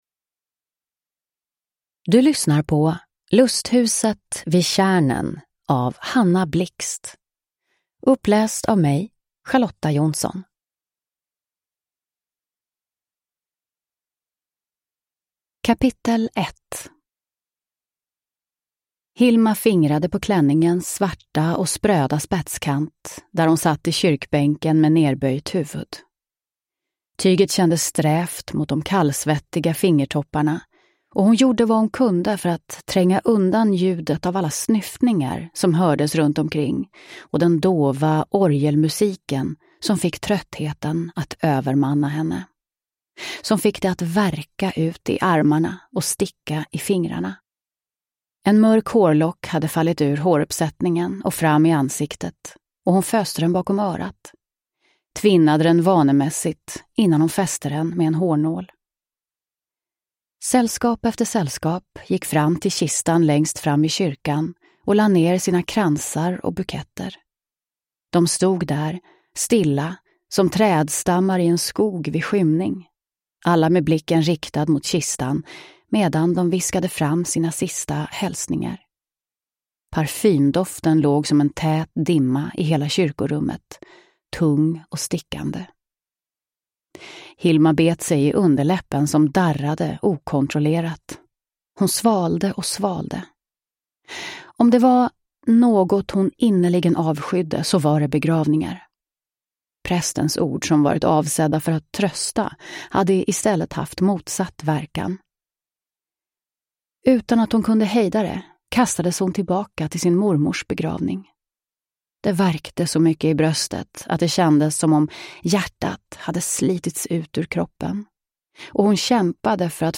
Lusthuset vid tjärnen – Ljudbok – Laddas ner